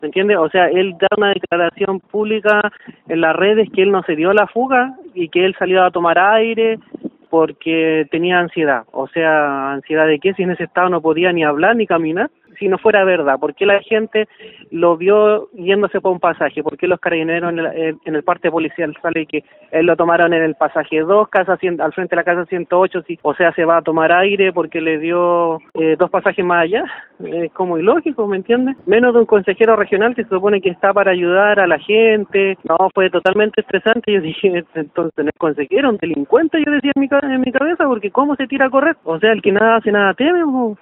En conversación con Radio Bío Bío, relató que iba a retirar a su hijo del colegio, cuando sucedió el accidente.